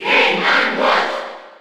Category:Crowd cheers
Mr._Game_&_Watch_Cheer_Spanish_PAL_SSB4.ogg